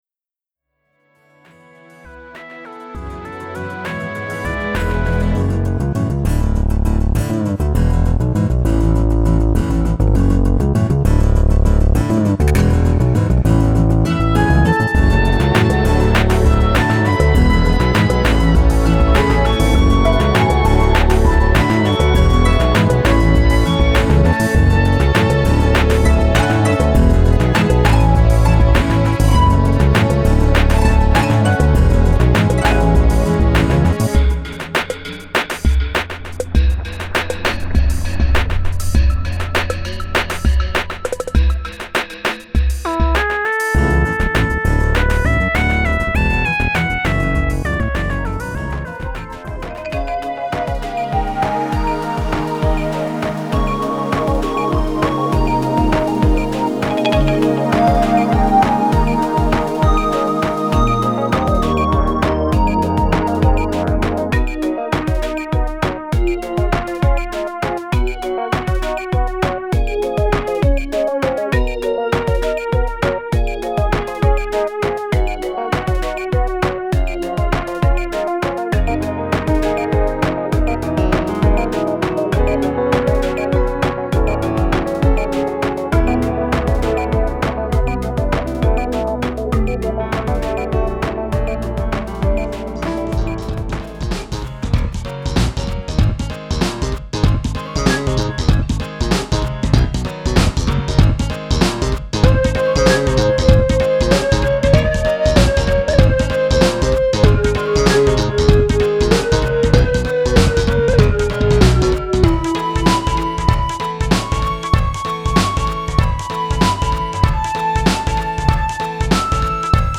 ■クロスフェード